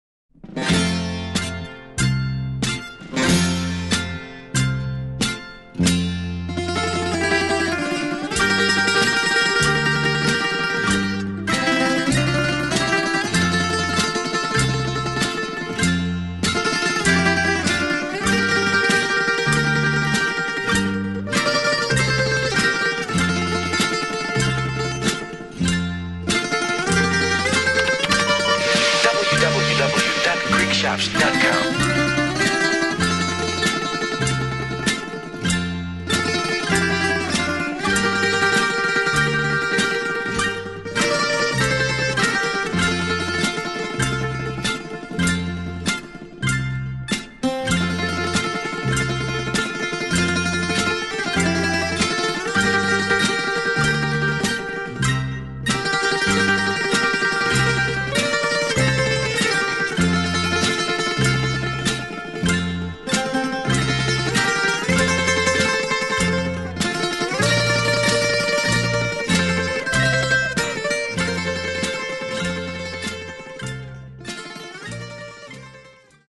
14 great instrumentals from a great composer
Bouzouki soloist